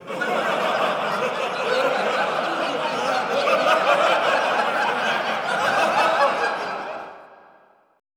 LAUGHTER 2-R.wav